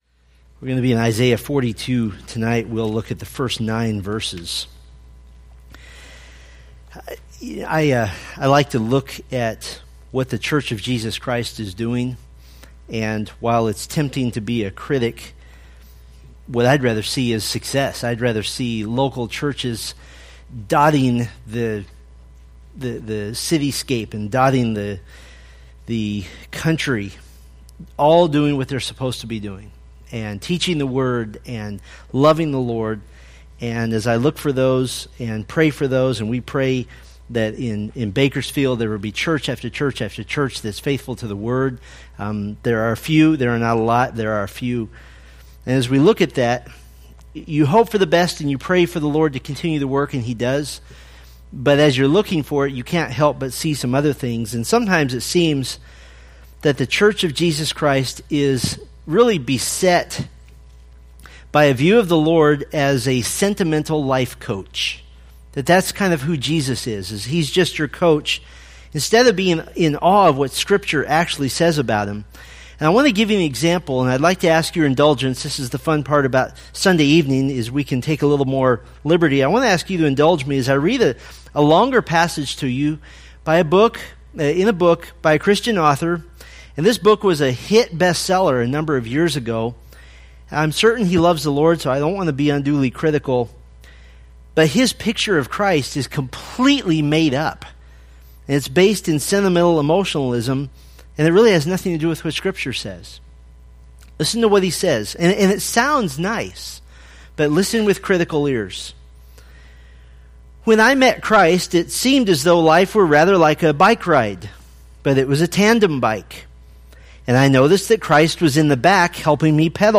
Preached February 5, 2017 from Isaiah 42:1-9